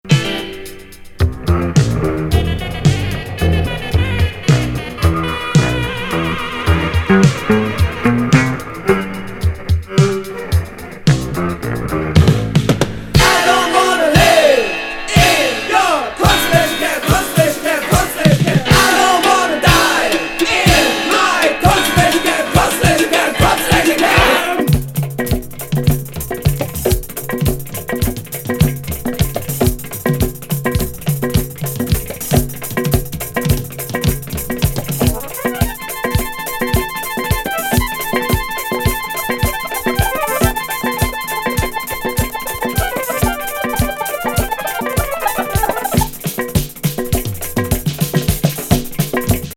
録音82年作2LP!ズッポリ・アラビック・サイケ世界。